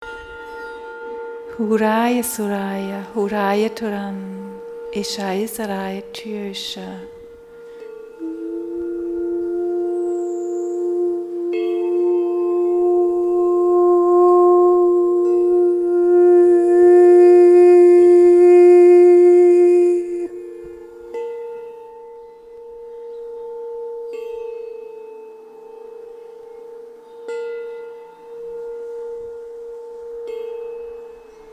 Tauche ein in die faszinierende Welt der Klangskulpturen.
Entdecke hörend das Klangspiel verschiedener Materialien.